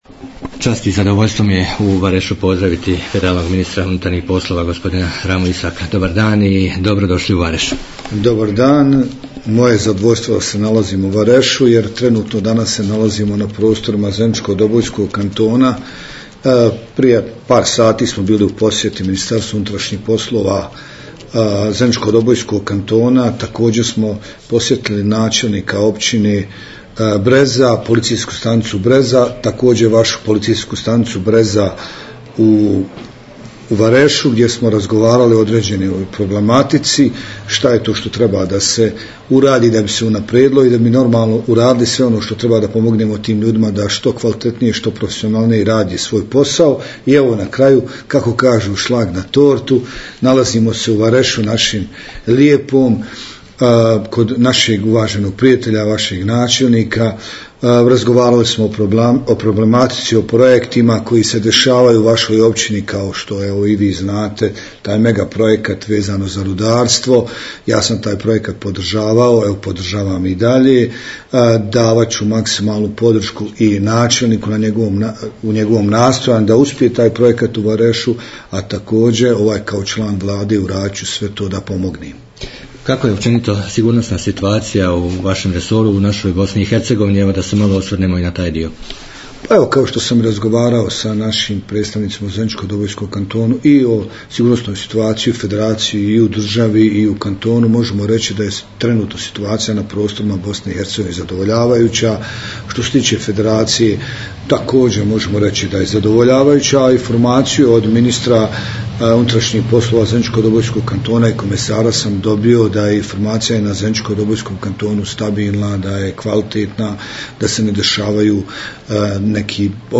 Nakon sastanka ministar je dao izjavu za radio Bobovac i izrazio zadovoljstvo današnjom posjetom.